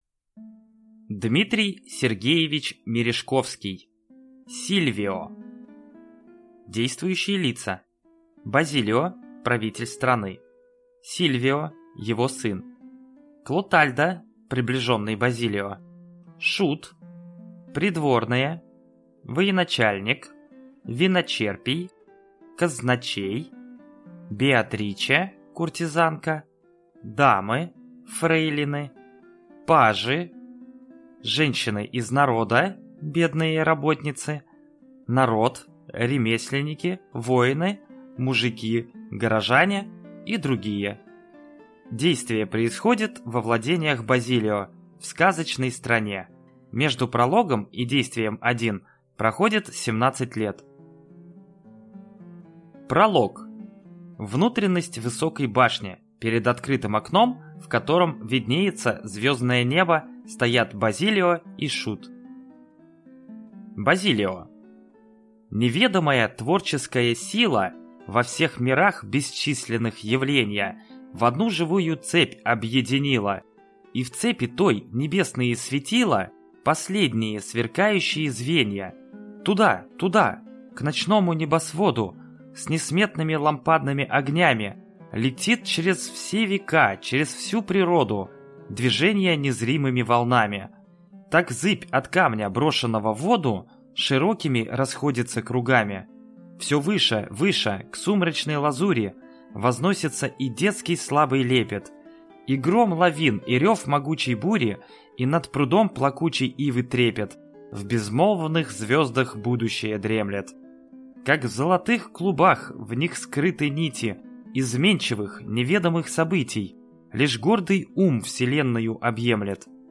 Аудиокнига Сильвио | Библиотека аудиокниг